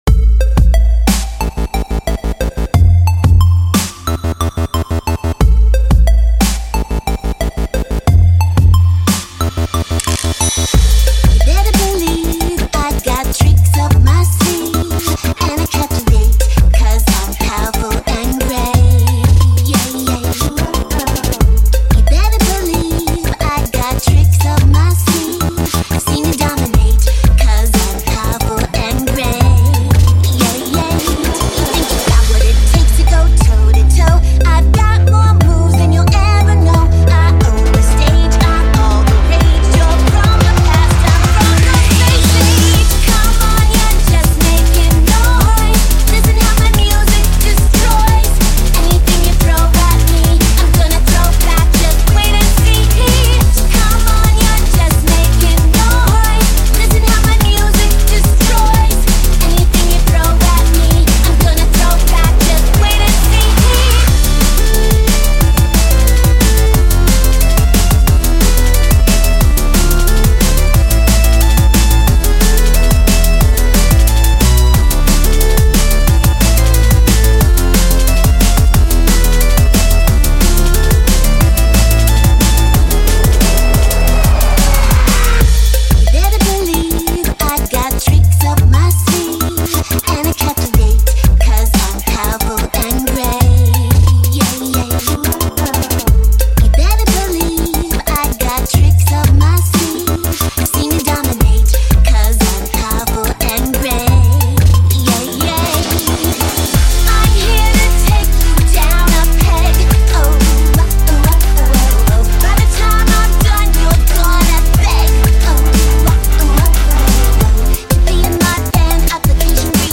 genre:remix